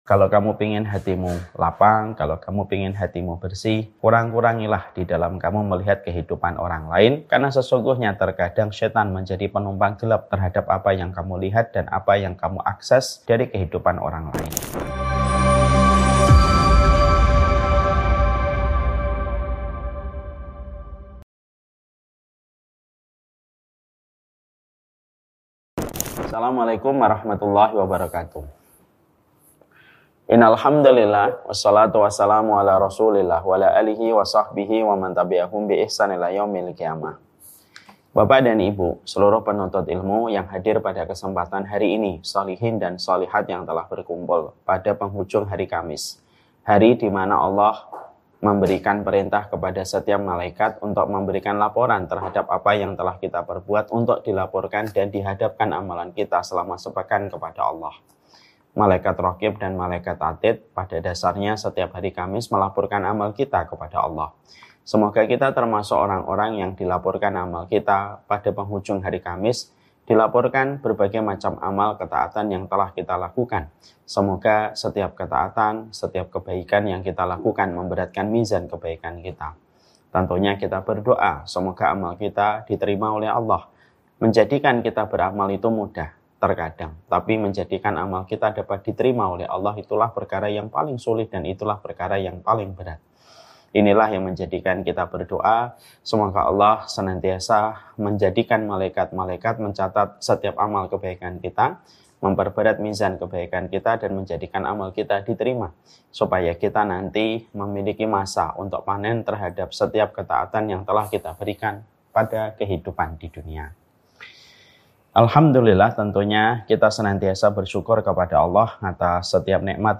Kajian ini menekankan bahwa salah satu penyebab hati menjadi sempit dan gelisah adalah terlalu sering melihat dan membandingkan kehidupan orang lain.